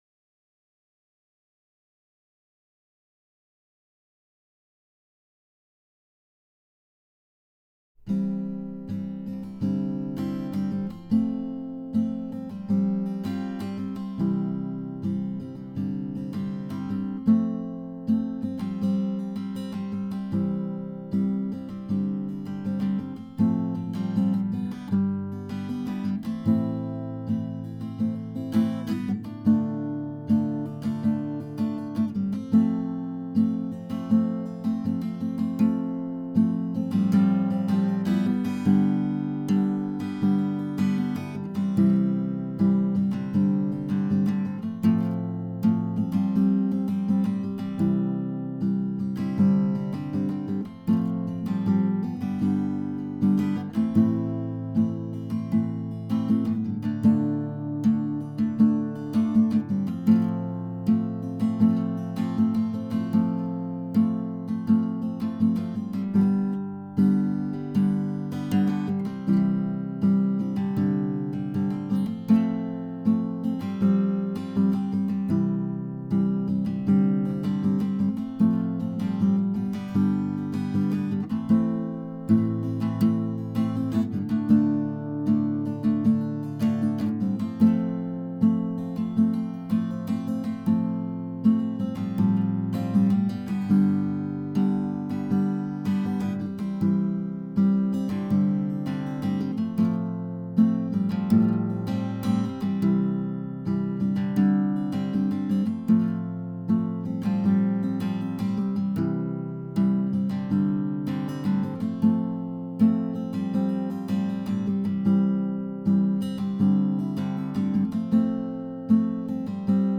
acousticguitar.wav